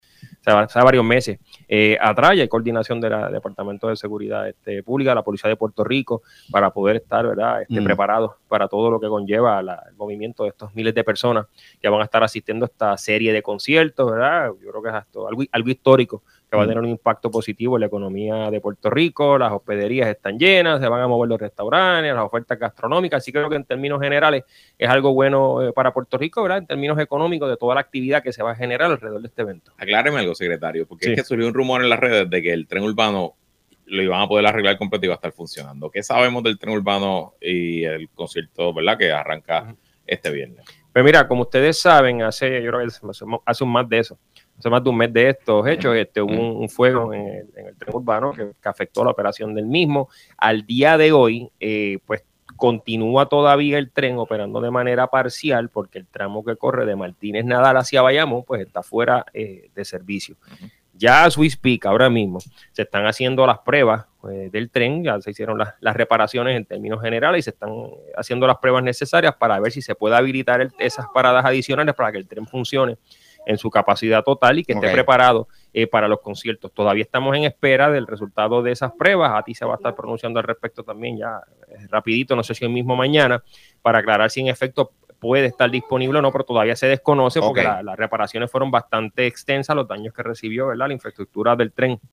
El secretario de Asuntos Públicos, Hiram Torres aseguró en Puestos Pa’ la Mañana que el gobierno está preparado para el inicio de la residencia ‘No me quiero ir de aquí’ de Bad Bunny, pautada para comenzar este próximo viernes, 11 de julio.